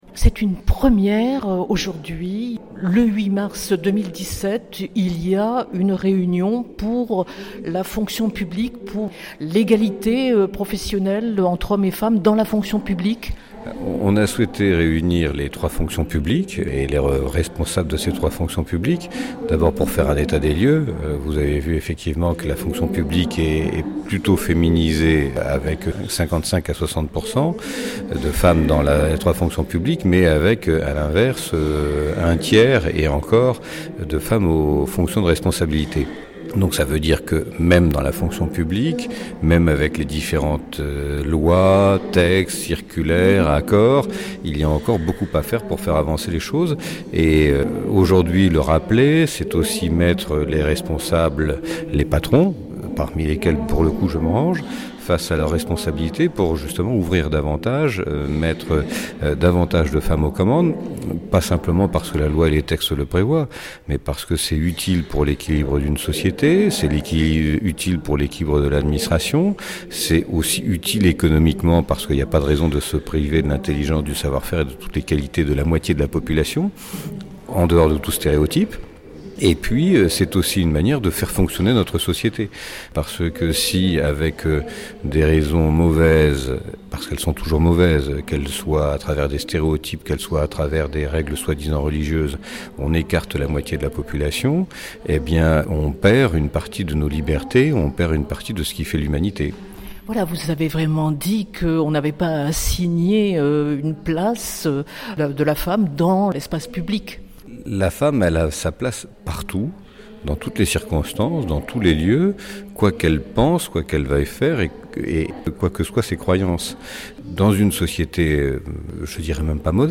À l’occasion de la journée internationale des femmes, la délégation régionale aux droits des femmes a organisé une réunion sur l’égalité professionnelle dans la fonction publique, en présence de Stéphane Bouillon, préfet de la région Provence-Alpes-Côte d’Azur.